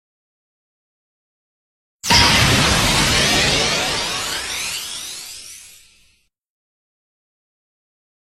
Mp3 Sound Effect